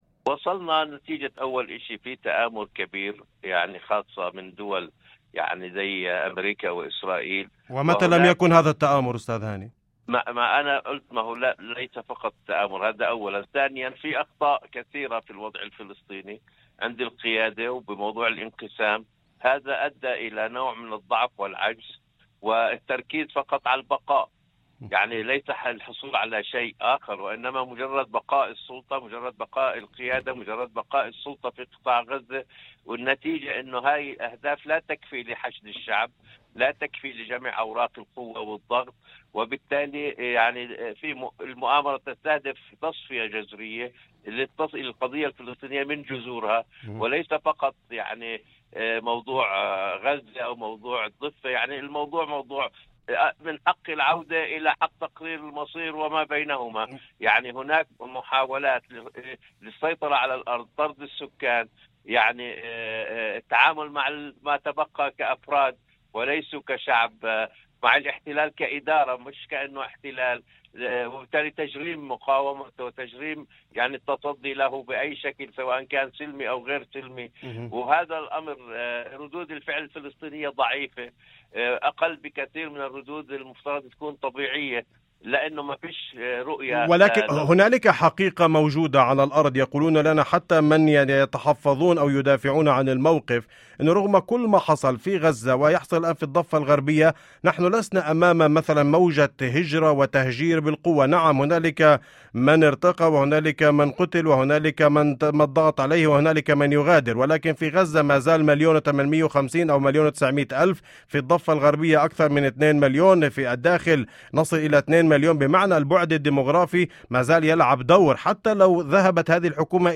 في مداخلة هاتفية ضمن برنامج "أول خبر" على إذاعة الشمس